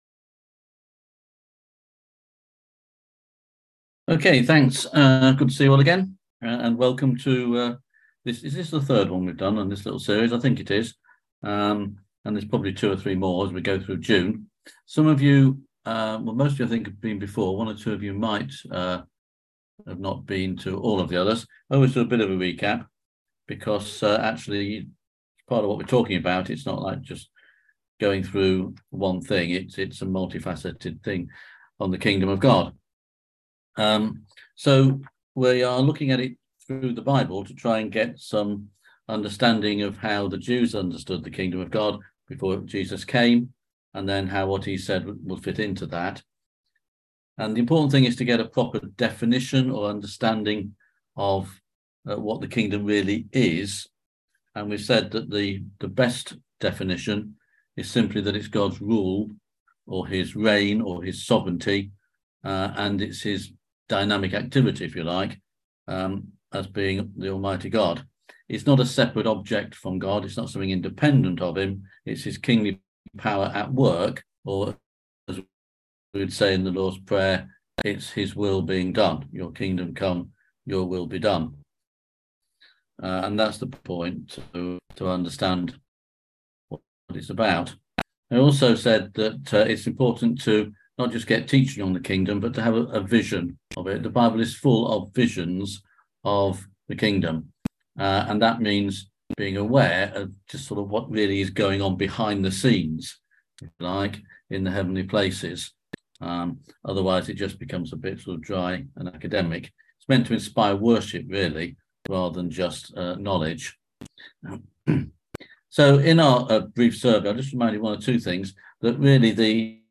On June 8th at 7pm – 8:30pm on ZOOM